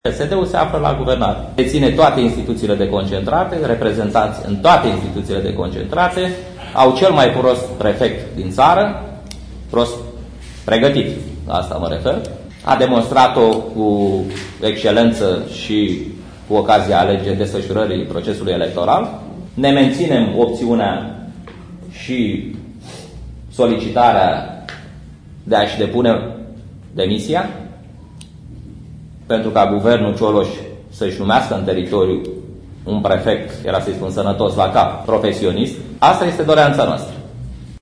Președintele PNL Buzău, Ovidiu Anghel l-a atacat din nou pe prefectul județului Buzău, George Sava. Într-o conferință de presă în care a arătat cât de apreciat este de conducerea centrală a PNL care nu i-a acceptat demisia datorită rezultatului de excepție scos la Buzău, Anghel s-a lansat într-o serie de acuzații la adresa prefectului de Buzău presărate cu atacuri la persoană disimulate după un model patentat de un alt deputat de Buzău.